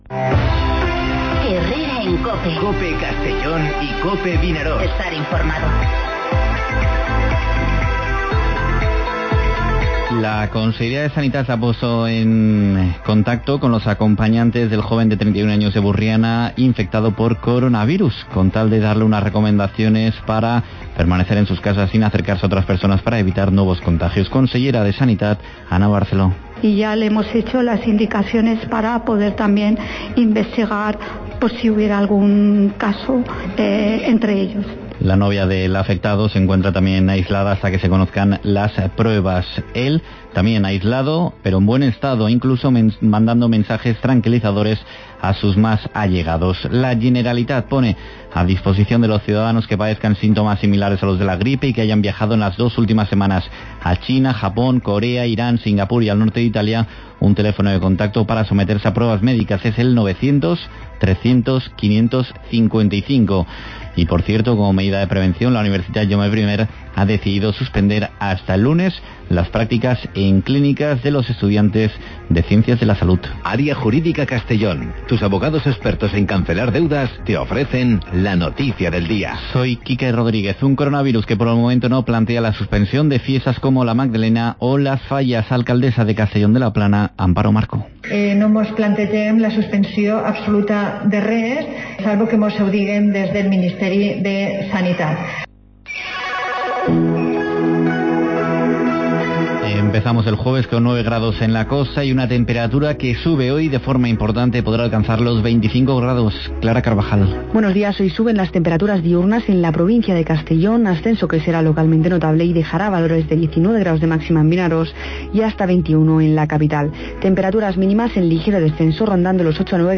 Informativo Herrera en COPE en la provincia de Castellón (27/02/2020)